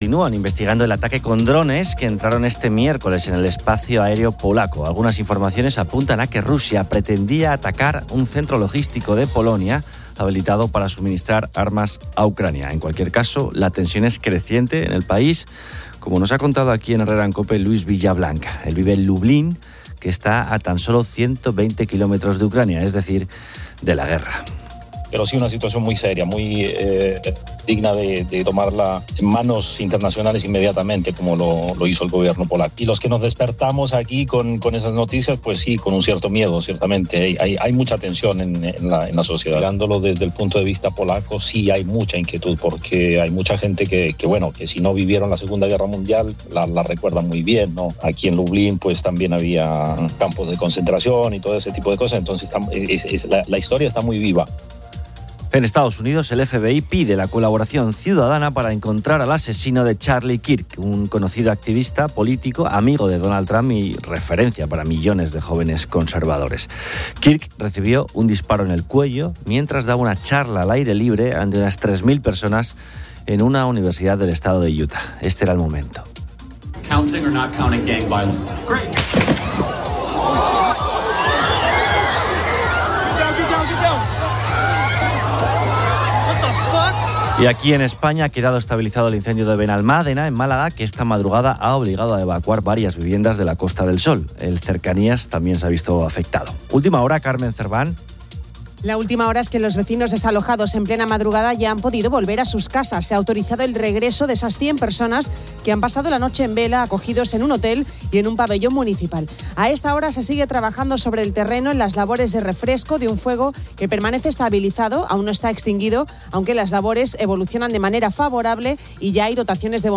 COPE abre sus micrófonos para que los oyentes recomienden a quién seguir en redes sociales.